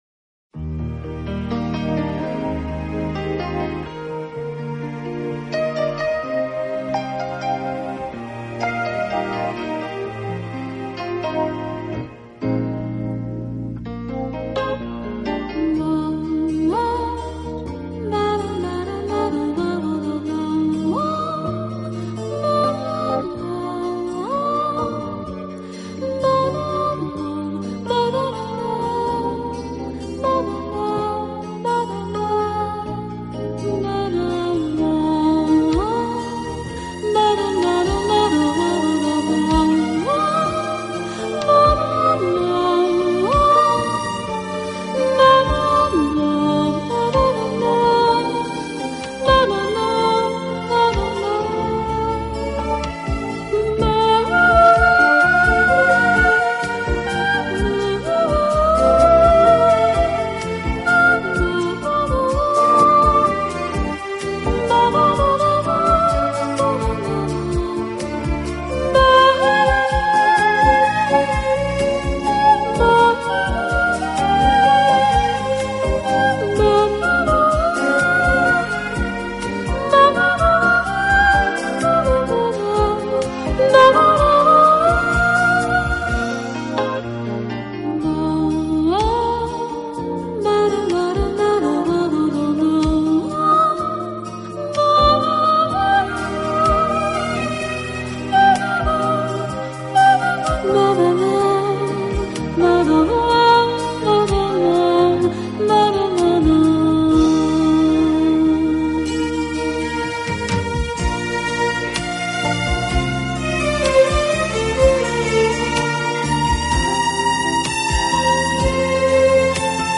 其风格清新明朗，华丽纯朴，从不过分夸张。